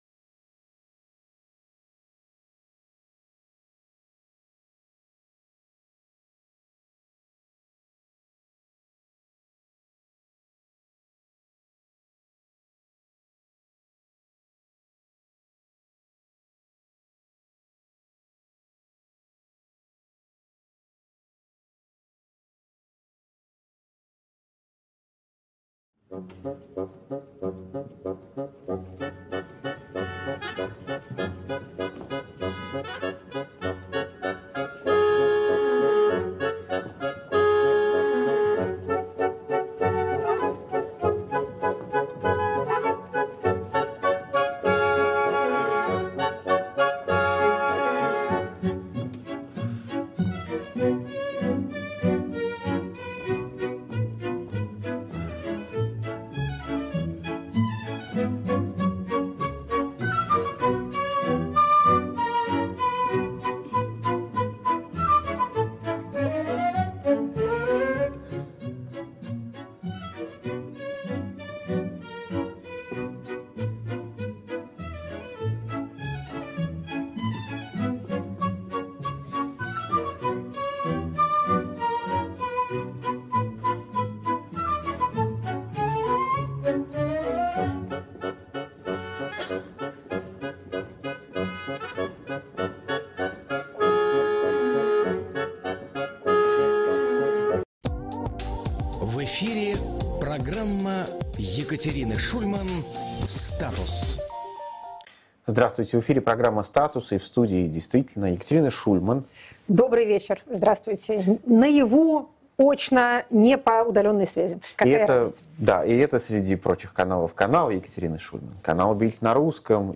Мы действительно, сегодня — какая радость! — вещаем вдвоем из студии в Берлине.